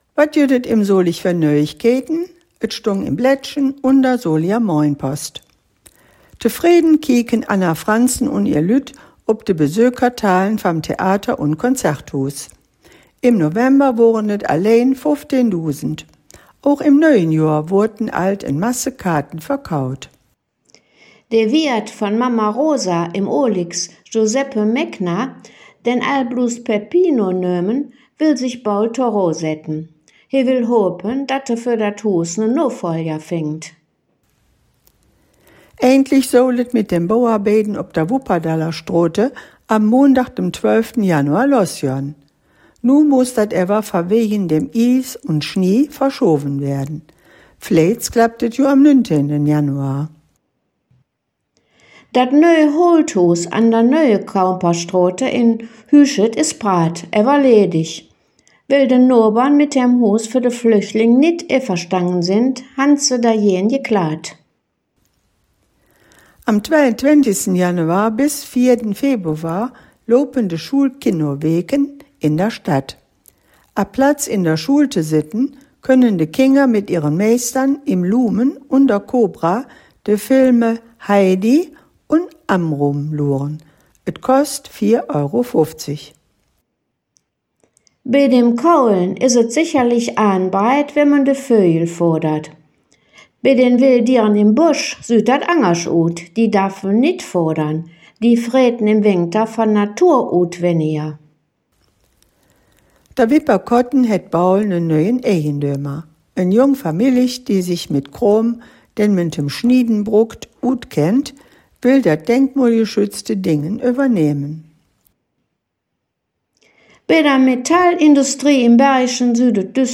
Solinger Platt Nachrichten 26/03
Nöüegkeïten op Soliger Platt